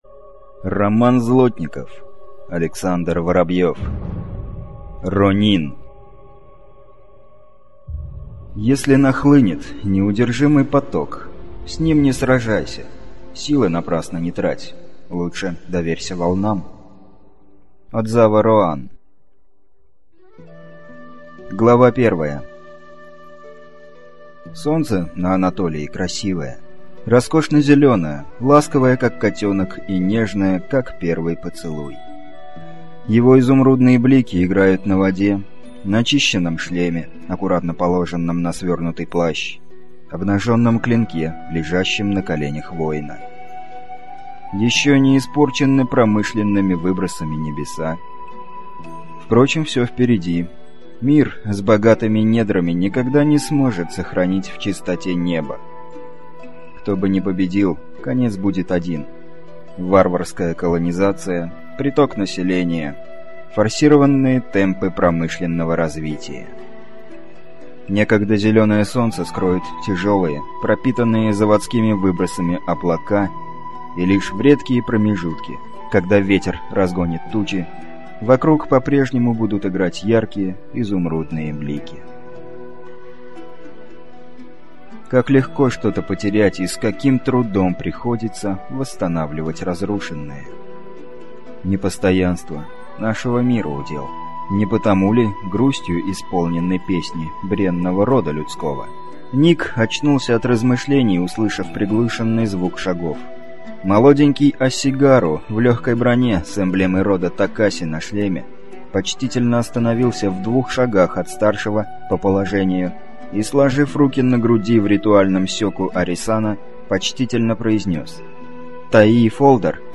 Аудиокнига Ронин | Библиотека аудиокниг